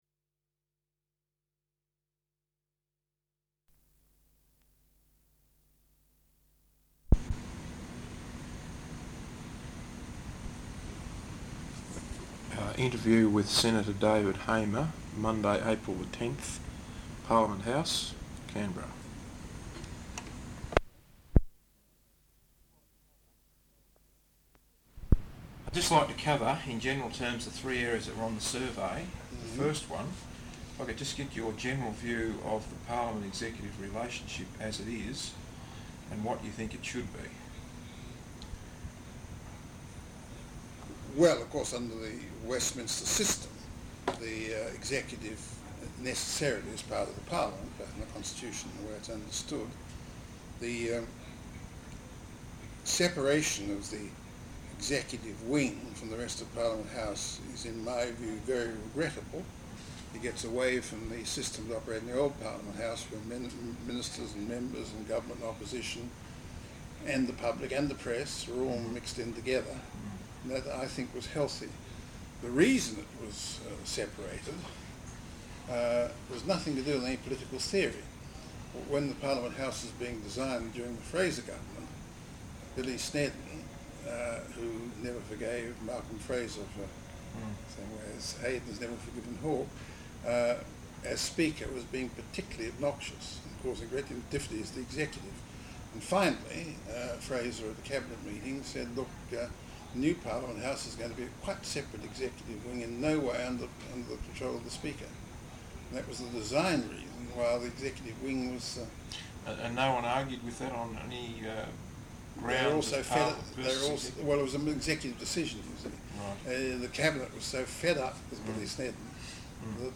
Interview with Senator David Hamer, Liberal Senator for Victoria, Monday April 10th, Parliament House, Canberra.